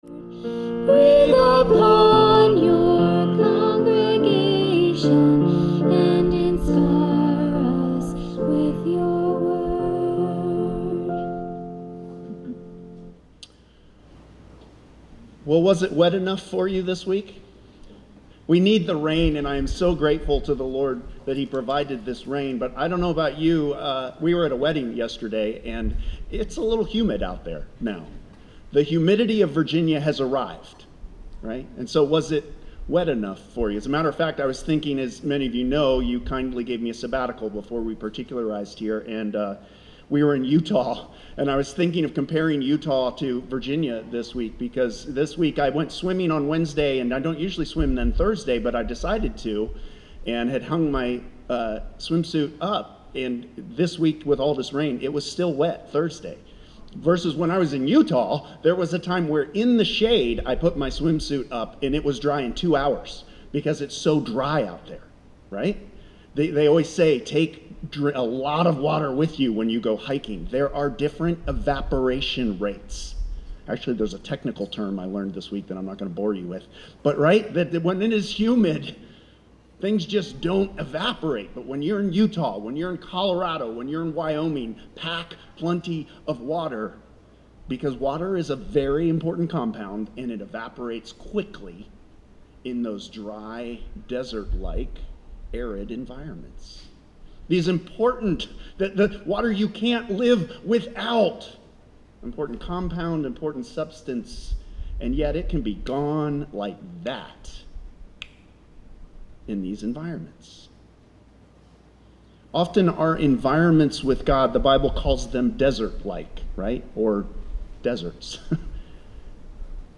Sermons | Hope Presbyterian Church of Crozet